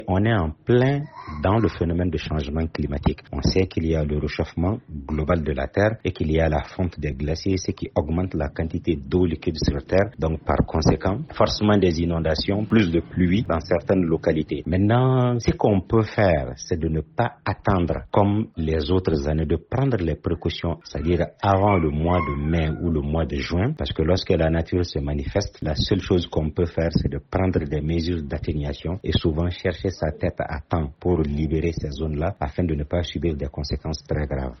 REACTION-ENVIRONNEMENTALISTE.mp3